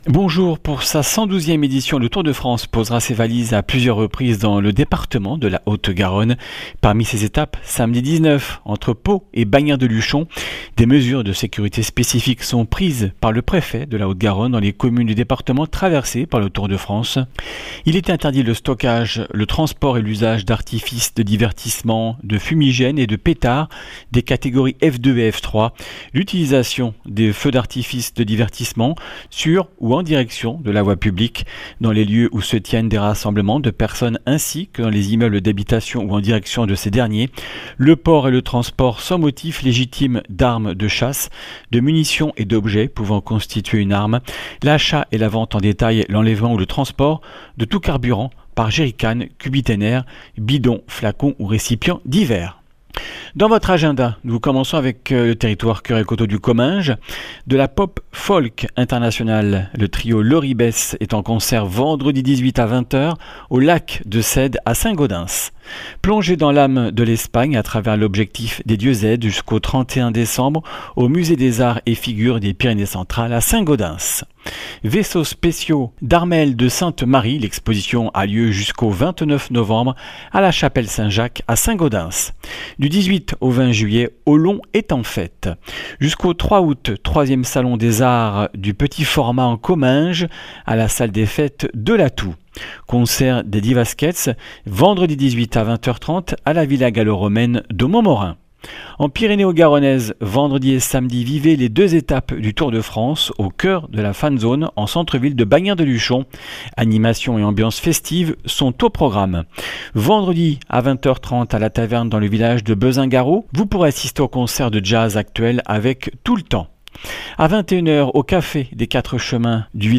Une émission présentée par
Journaliste